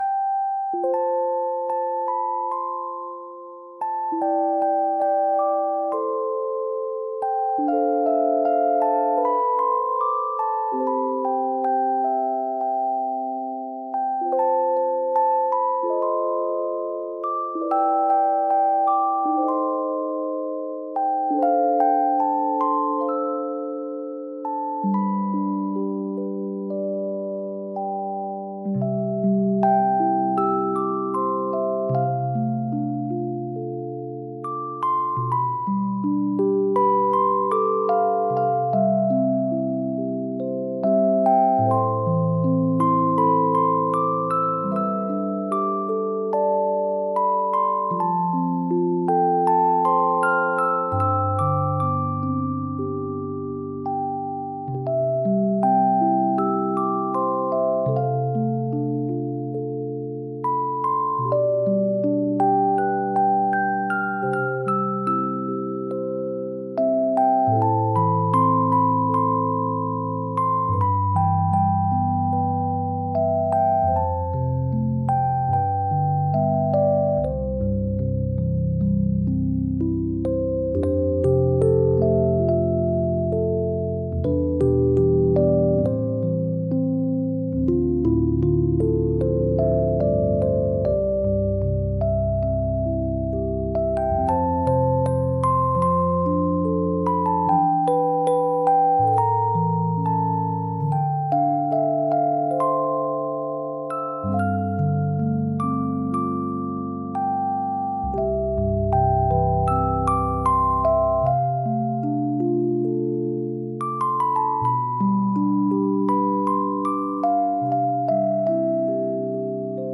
Детские колыбельные